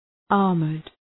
armoured.mp3